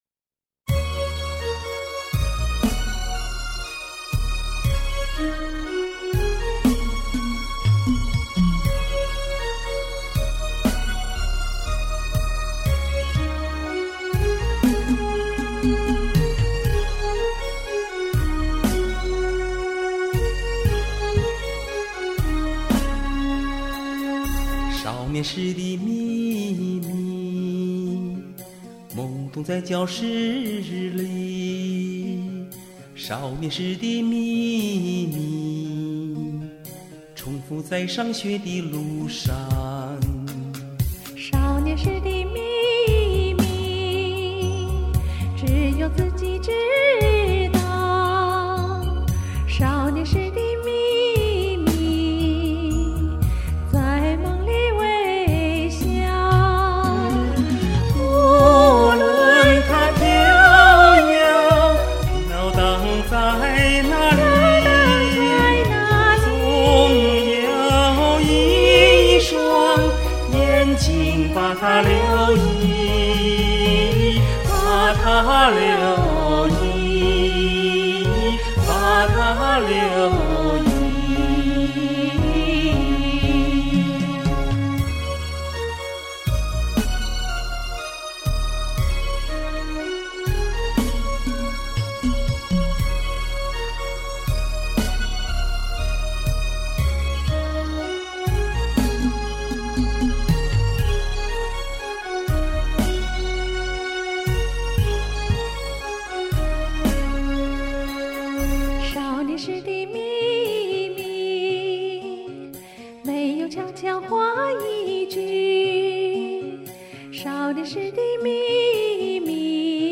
这首歌的原伴奏是C调
但受录音条件的限制，效果不能令人满意。
下载：伴奏C调（带旋律）伴奏E调 , 重唱